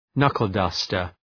Προφορά
{‘nʌkəl,dʌstər}